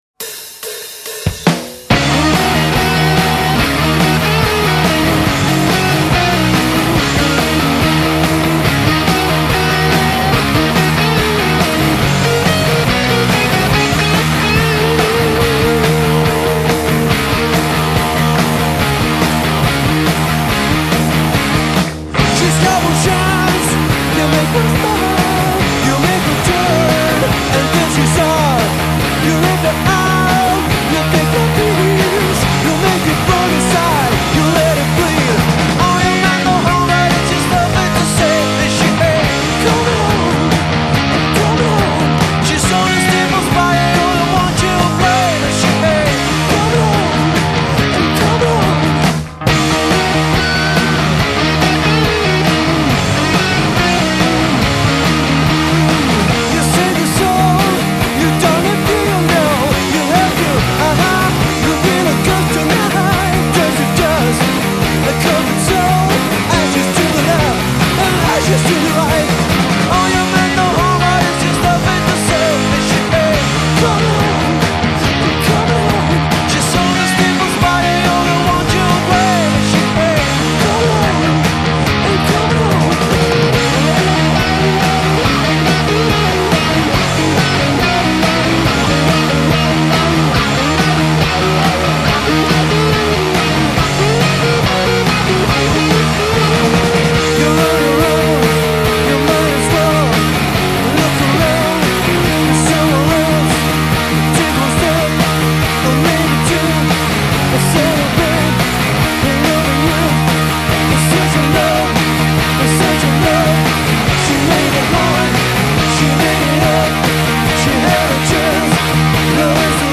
Vocal, Guitar
Bass
Guitar